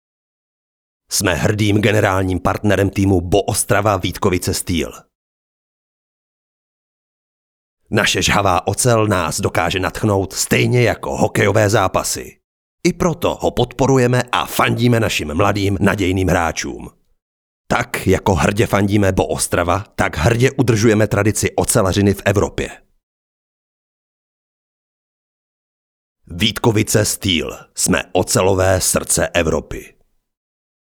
Mužský voiceover do Vašich videí
Nabízím mužský voiceover středního věku.